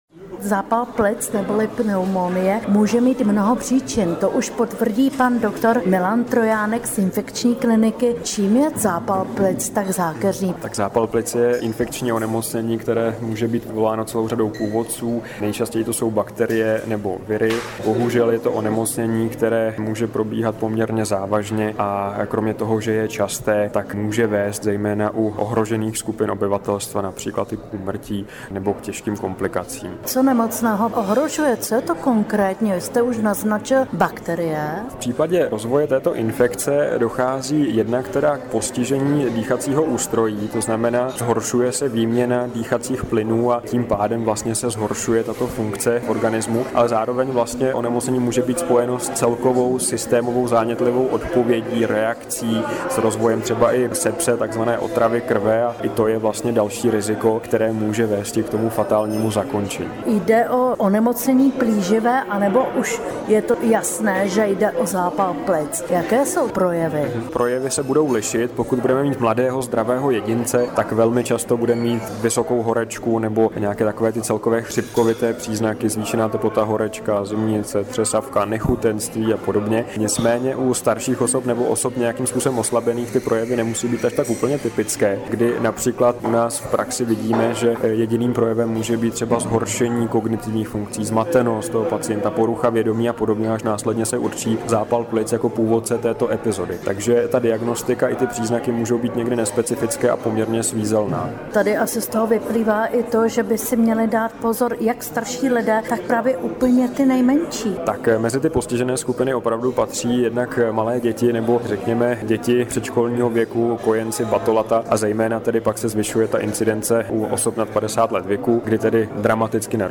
Rozhovor s odborníky o zákeřnosti zápalu plic